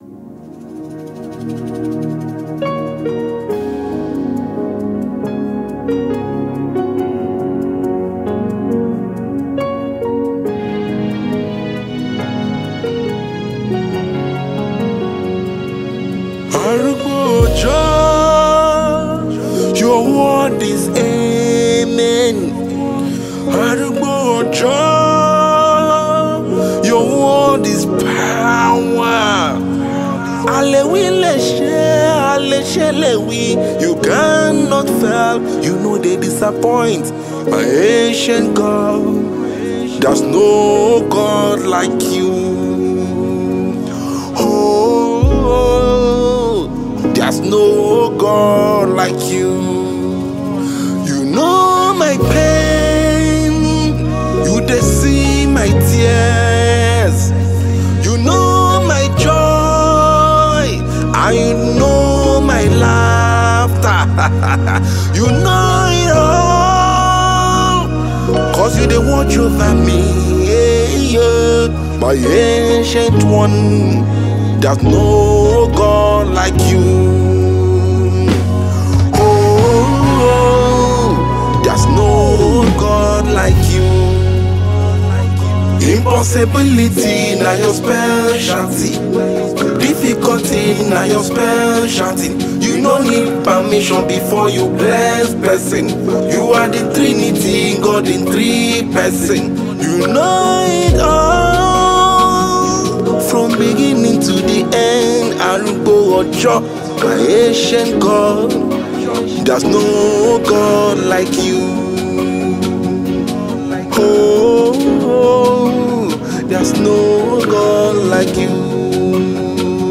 February 8, 2025 Publisher 01 Gospel 0
songwriter and saxophonist.
traditional African rhythms